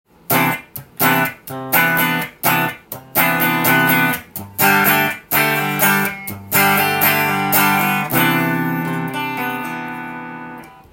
トーンのノブを上げるとタップスイッチになっているので
シングルコイルに変換してくれます。細い音がジャキジャキして気持ちいいですね。